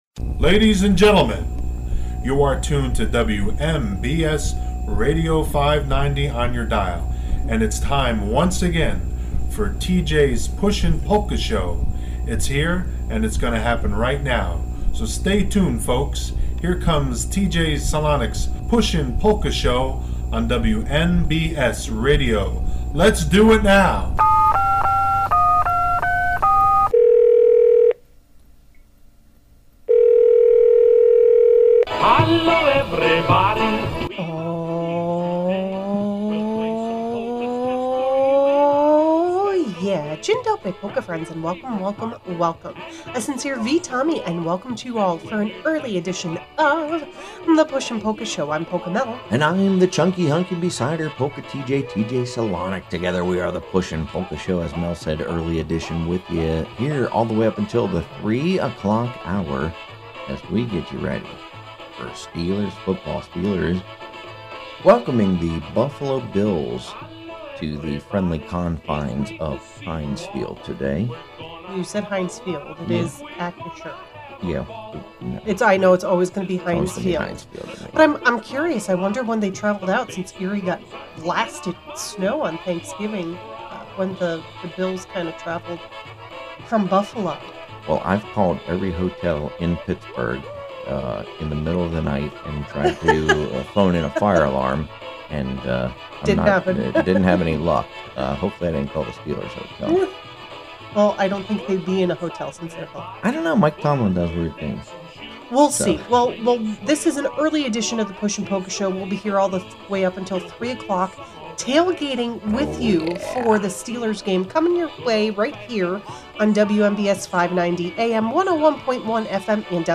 Polkas